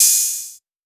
TM-88 Hat Open #05.wav